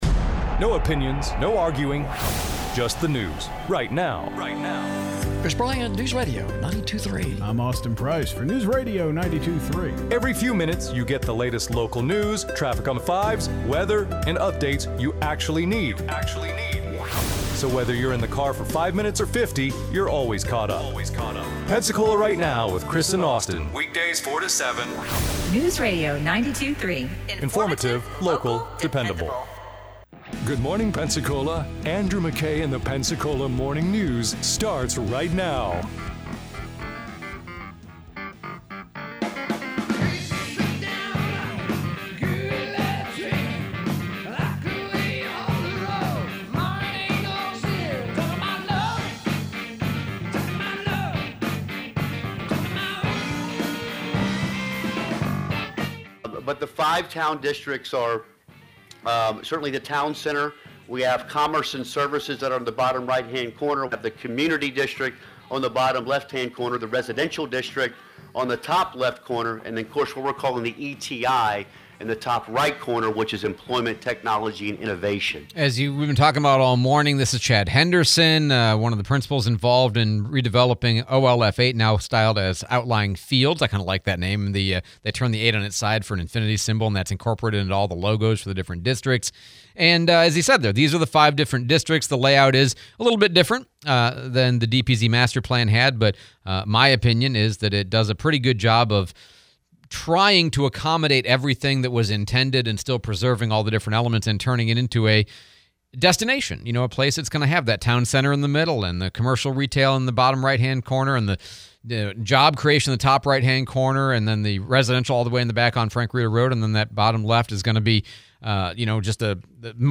OLF 8 discussion, Congressman Patronis interview